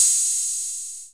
HAT 606 OP1R.wav